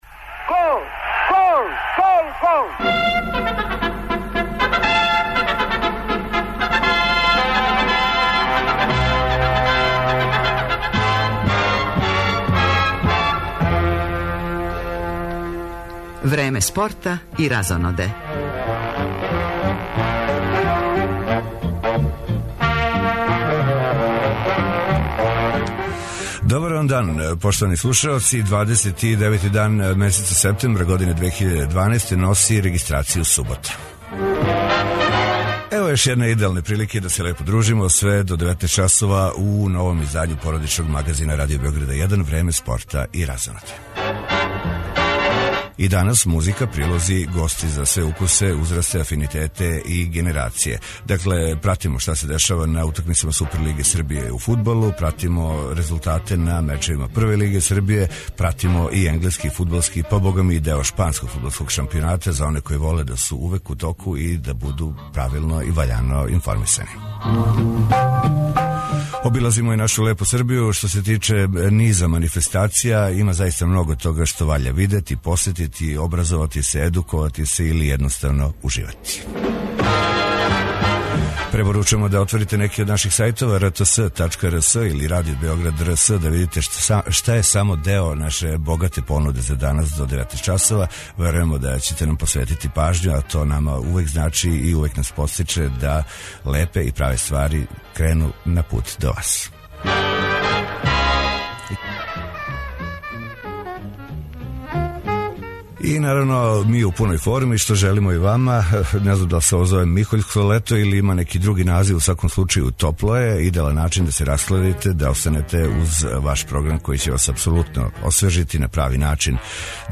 Радио Београд 1, 15.30